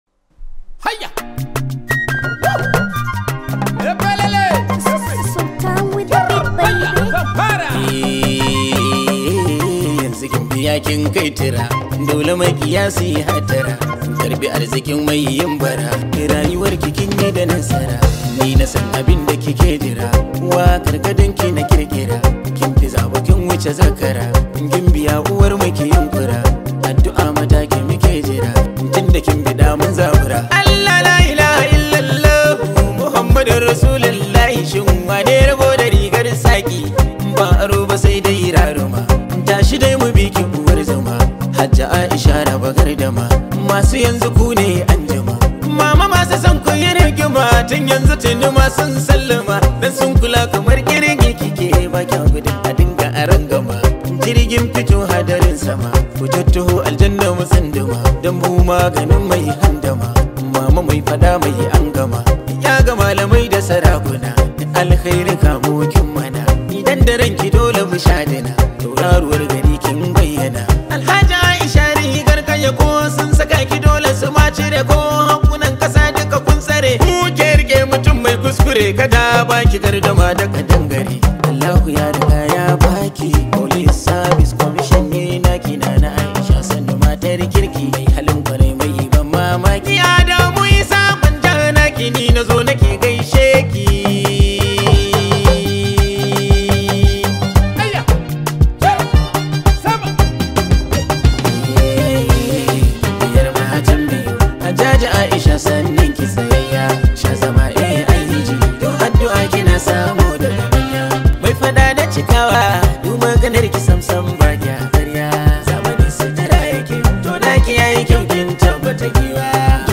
This high vibe hausa song